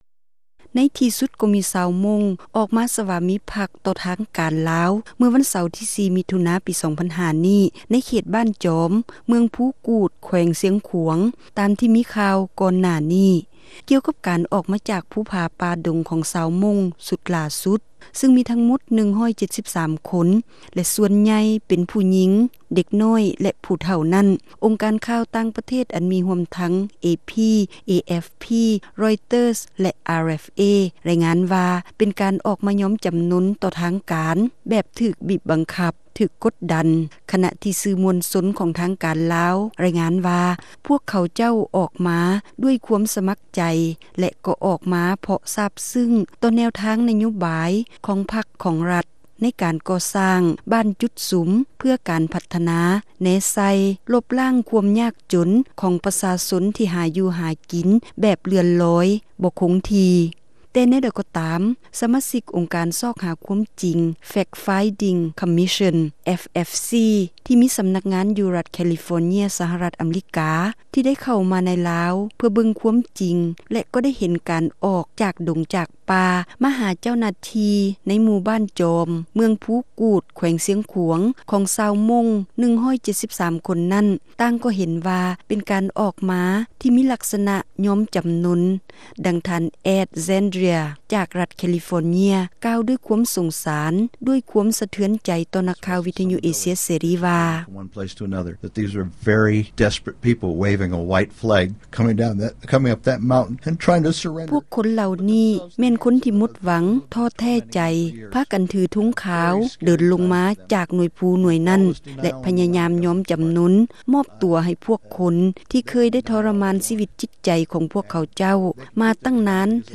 ວິເຄາະ ໂດຍ